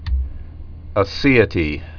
(ə-sēĭ-tē, ā-, -sā-)